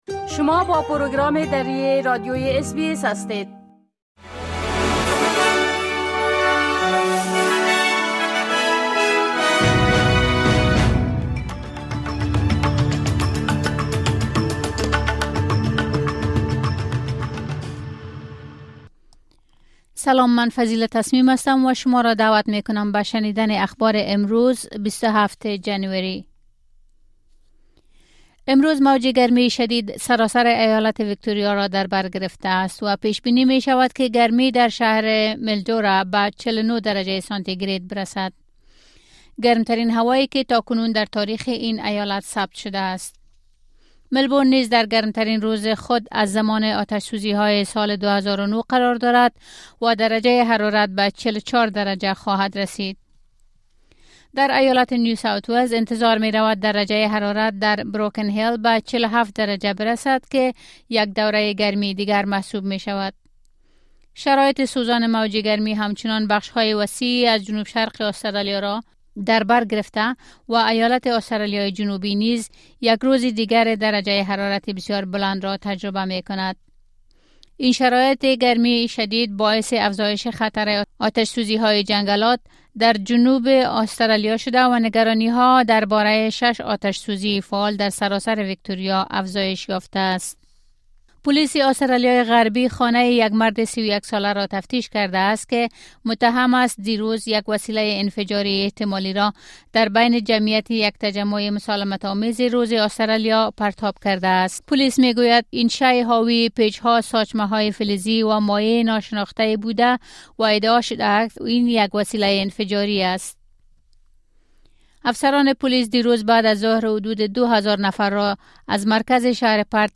خلاصه‌ای مهم‌ترین خبرهای امروز ۲۷ ماه جنوری ۲۰۲۶ به زبان درى از اس‌بى‌اس را در اين‌جا شنيده مى توانيد.